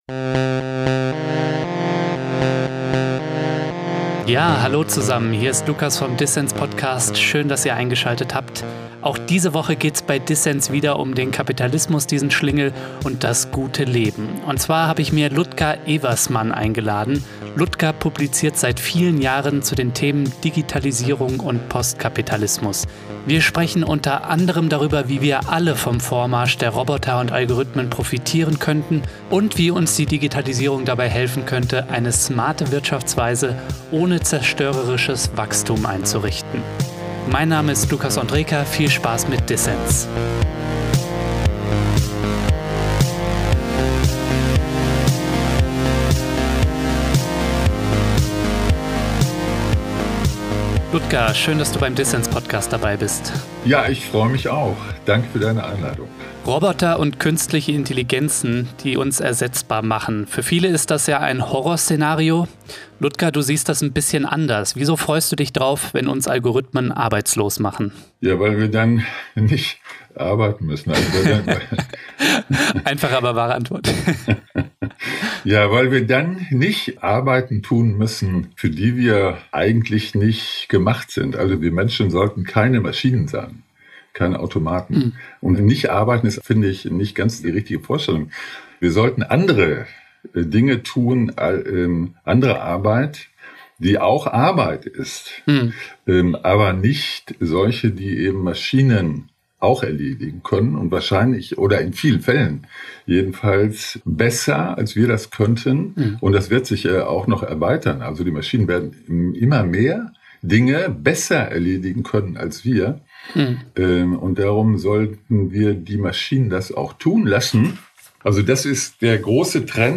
Roboter und Algorithmen könnten uns von lästigen Arbeiten befreien. Ein Gespräch über Maschinen in gesellschaftlicher Hand, eine nachhaltige Gemeinwohlökonomie und Sneaker aus dem 3D-Drucker.